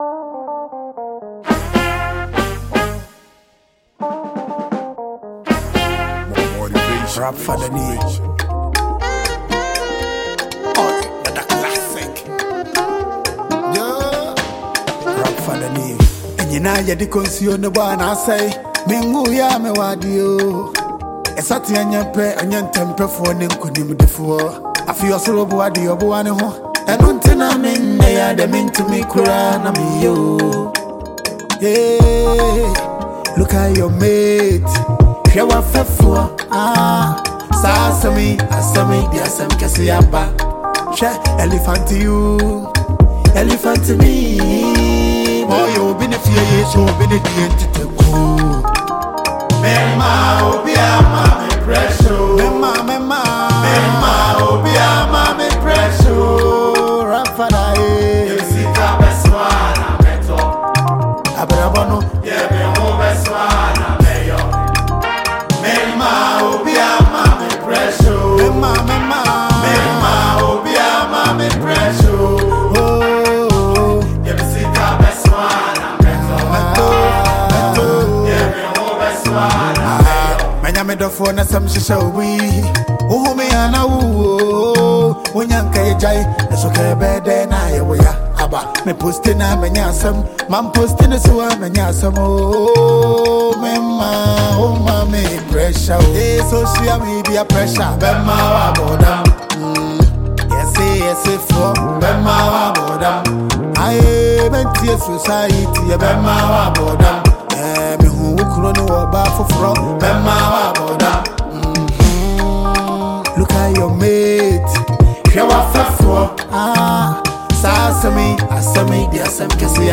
Renowned Ghanaian talented rapper and singer